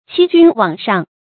欺君罔上 注音： ㄑㄧ ㄐㄩㄣ ㄨㄤˇ ㄕㄤˋ 讀音讀法： 意思解釋： 欺騙蒙蔽君主。